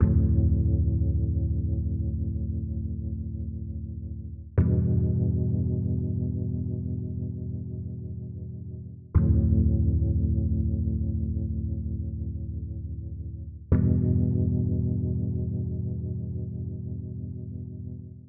爵士乐 Reharm Rhodes
描述：乌特里尔罗德斯环路
Tag: 89 bpm Jazz Loops Piano Loops 3.64 MB wav Key : C